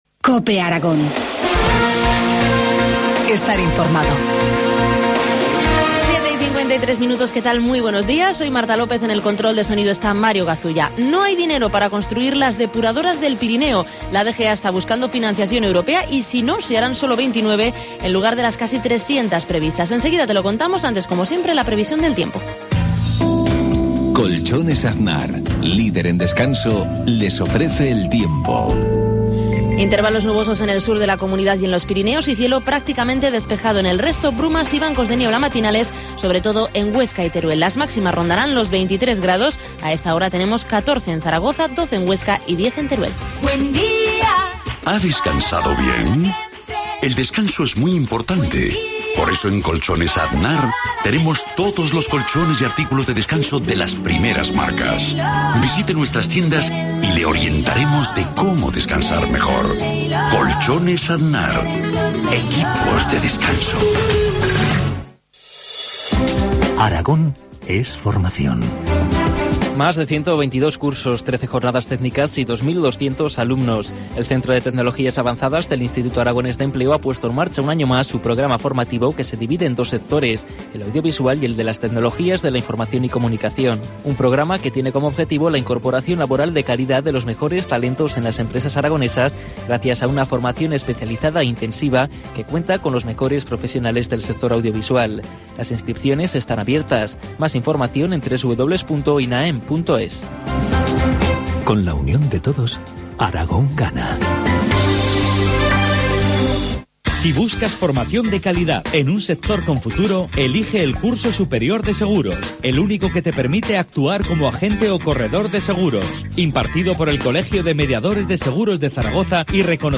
Informativo matinal, miércoles 9 de octubre, 7.53 horas